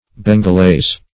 Search Result for " bengalese" : The Collaborative International Dictionary of English v.0.48: Bengalese \Ben`gal*ese"\, prop. a. Of or pertaining to Bengal.